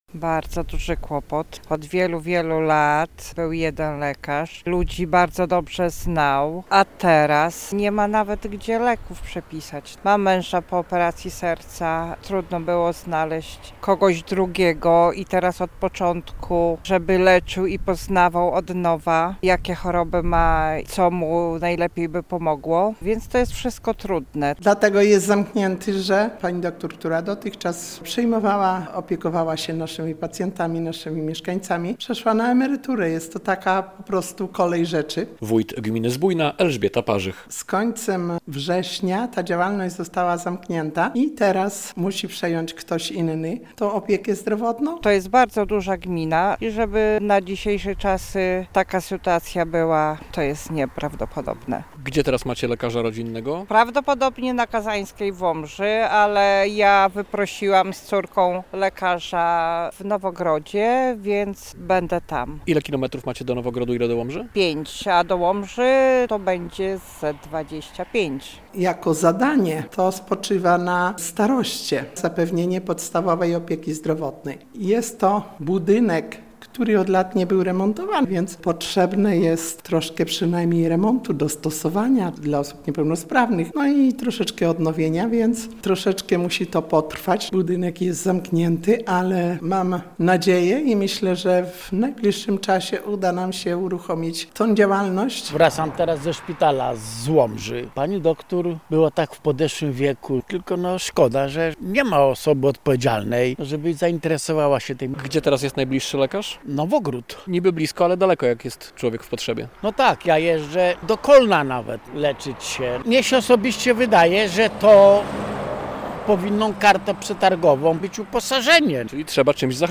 Ja jeżdżę do Kolna się leczyć - mówili mieszkańcy Zbójnej.
Na chwilę obecną budynek jest zamknięty, ale mam nadzieję i myślę, że w najbliższym czasie uda nam się uruchomić ten ośrodek - podkreśla wójt Zbójnej Elżbieta Parzych.